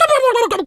turkey_ostrich_hurt_gobble_01.wav